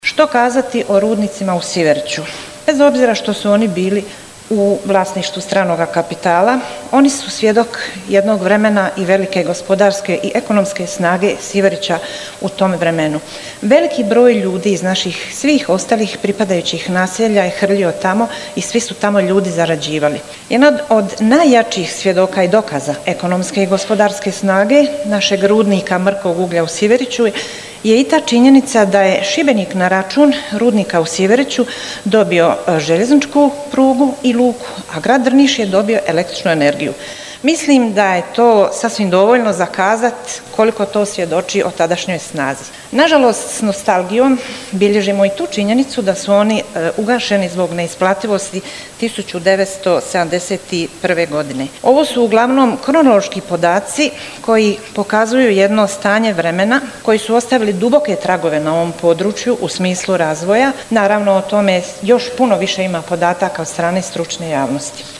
Znanstveni skup o Siveriću – pogled u povijest s ciljem jasnije budućnosti